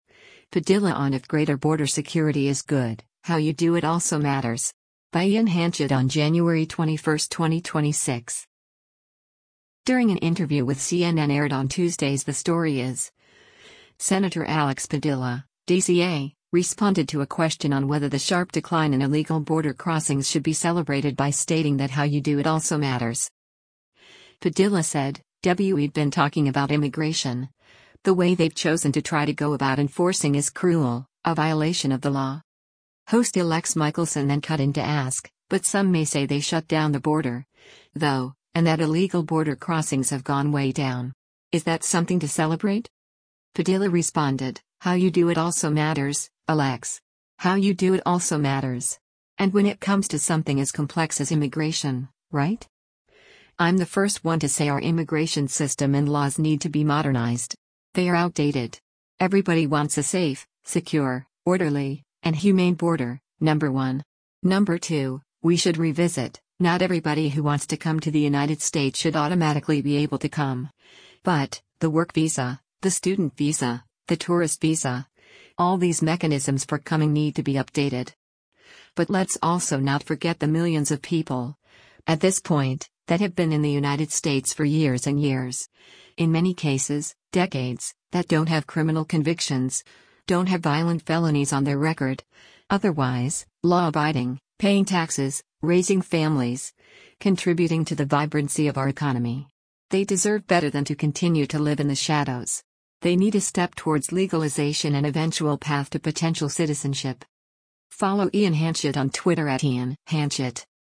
During an interview with CNN aired on Tuesday’s “The Story Is,” Sen. Alex Padilla (D-CA) responded to a question on whether the sharp decline in illegal border crossings should be celebrated by stating that “How you do it also matters.”